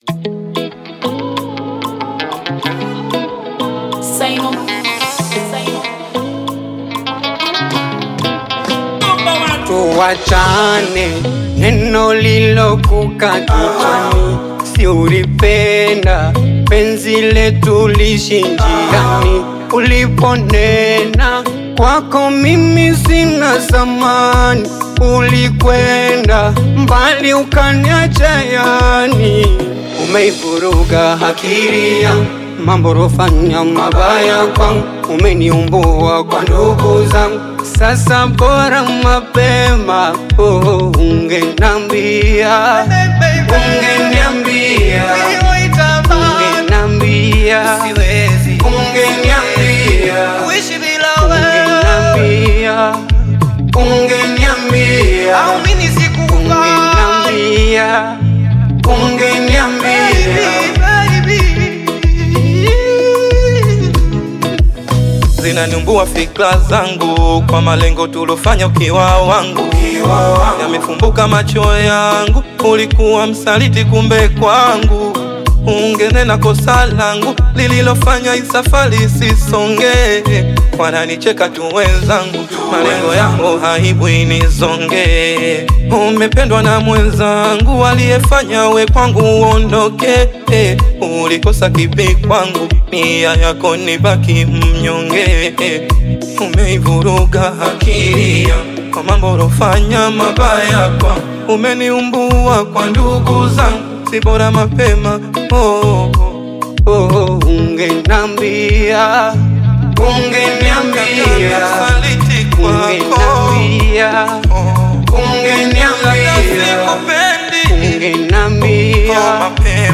AudioBongo flava
heartfelt Afro-Pop/Bongo Flava single